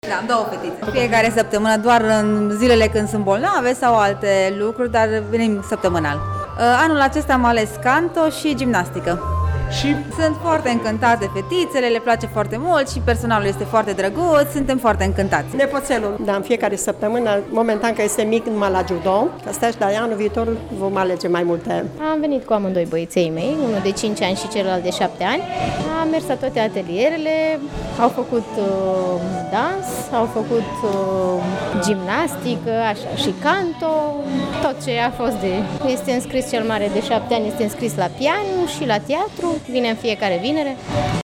Părinții se arată încântați de activitățile organizate la Palatul Copiilor, iar mulți dintre ei și-au înscris deja copiii la cursuri: